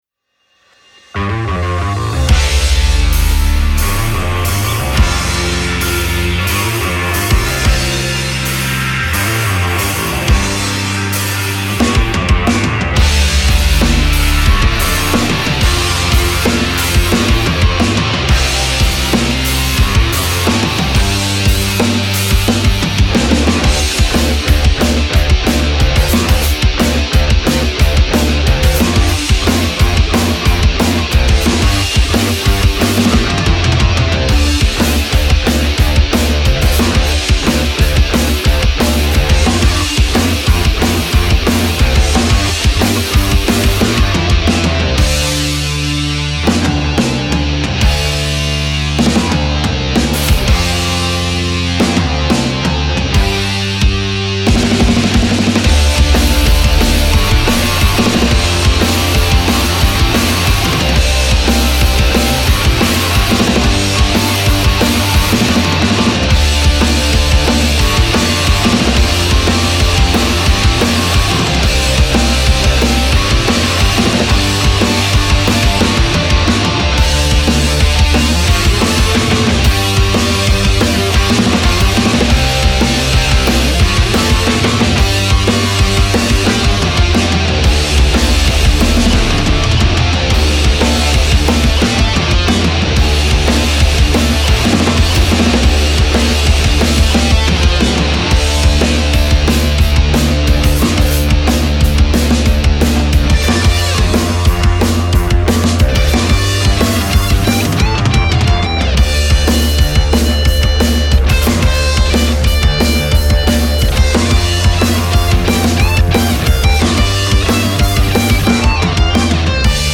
a song with boundless energy that builds to a soaring chorus